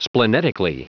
Prononciation du mot splenetically en anglais (fichier audio)
Prononciation du mot : splenetically